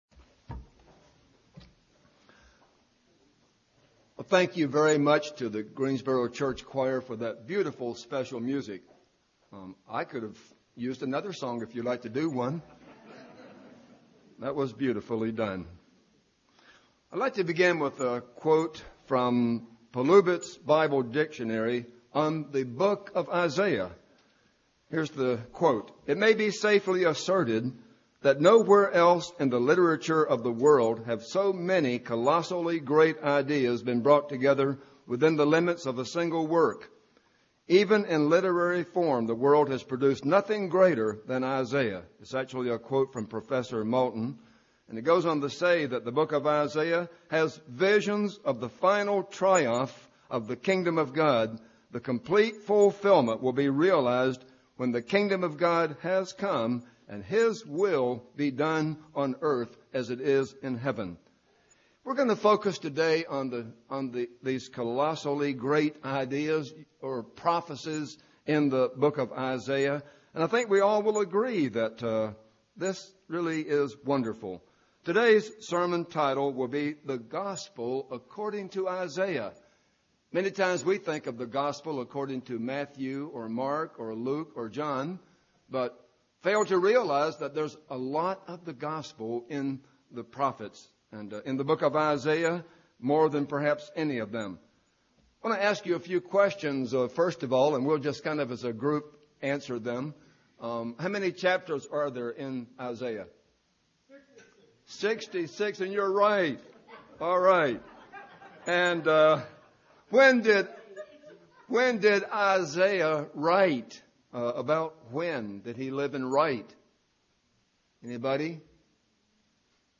This sermon was given at the Pigeon Forge, Tennessee 2014 Feast site.